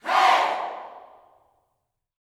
HEY  09.wav